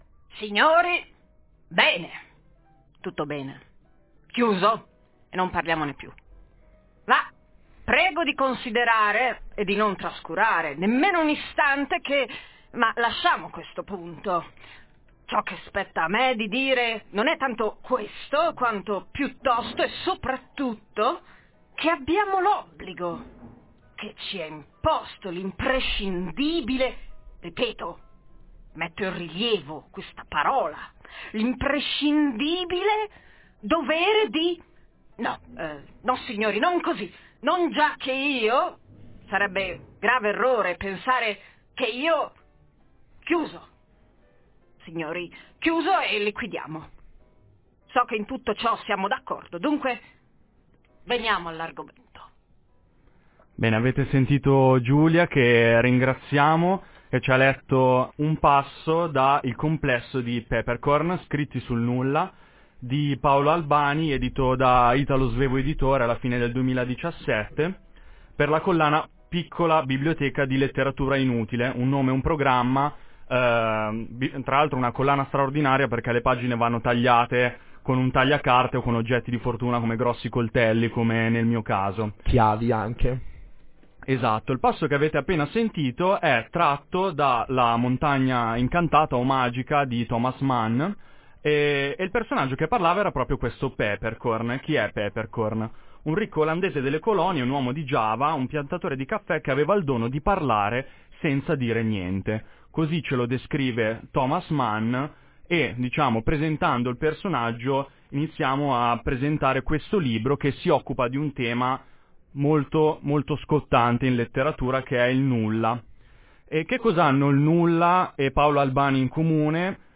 intervista sul libro